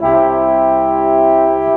Rock-Pop 01 Brass 04.wav